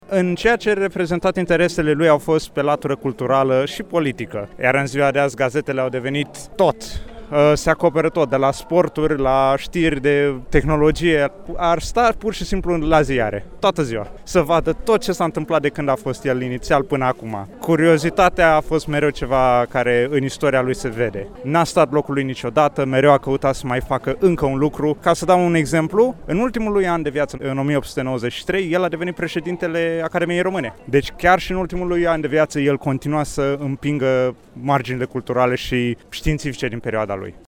Personajele istorice au revenit în  cadrul Retro Marktplatz, eveniment încă în desfășurare, și care rememorează atmosfera târgurilor Brașovului de odinioară.